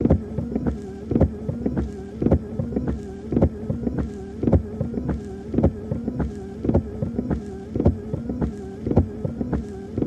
Saab Wiper Blades, Fast Speed, Looped, Interior